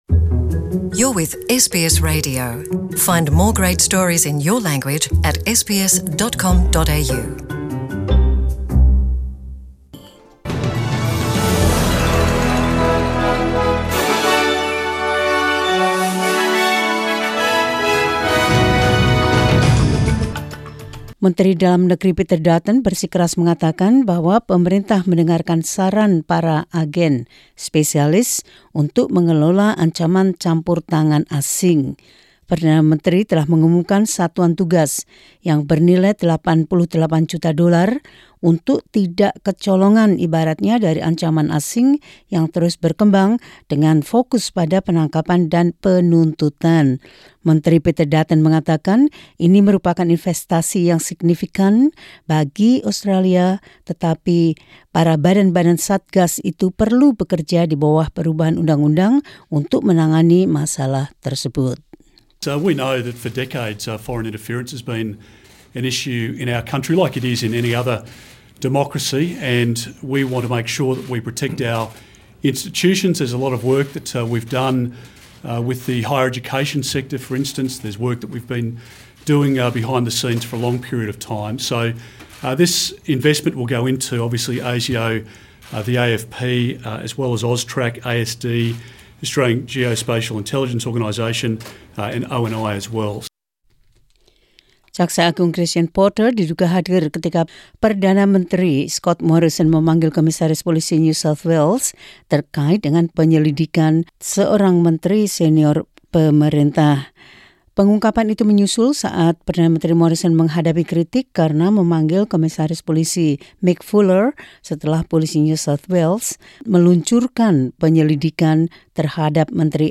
SBS Radio News in Indonesian 2 Dec 2019.